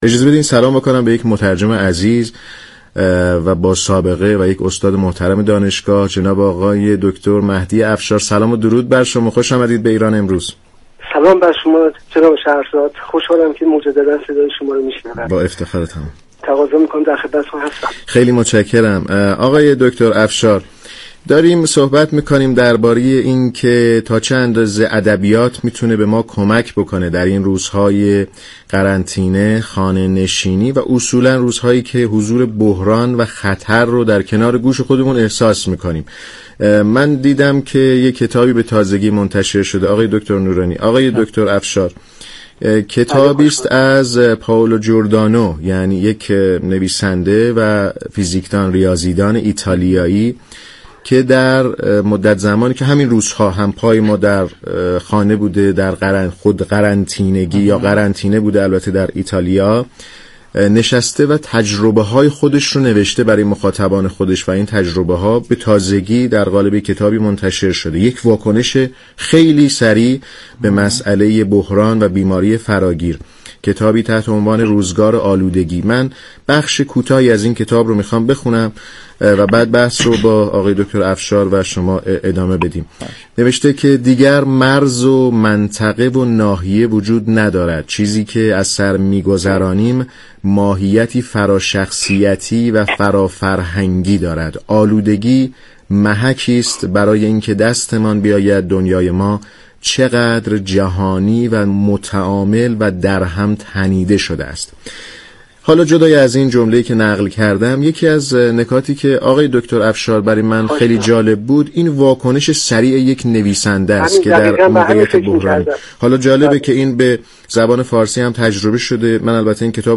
در گفت و گو با رادیو ایران